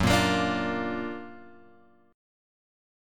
F# Augmented Major 7th